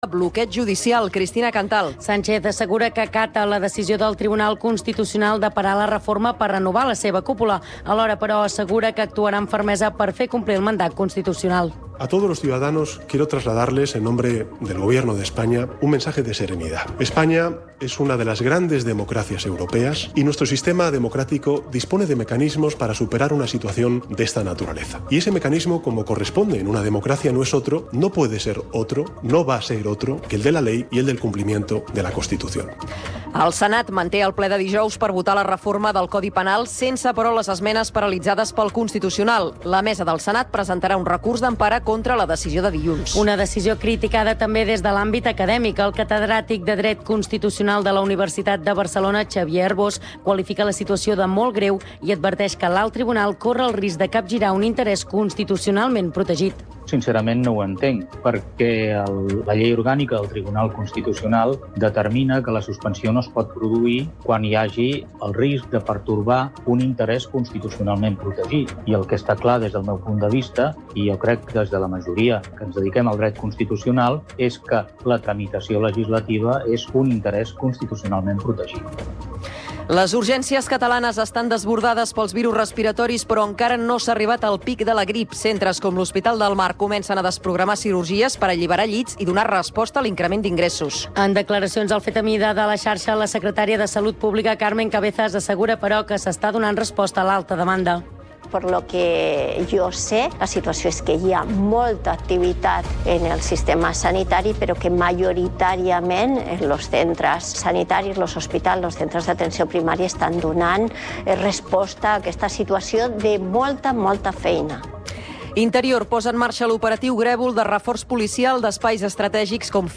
Informatiu en xarxa que fa difusió nacional dels fets locals i ofereix la visió local dels fets nacionals.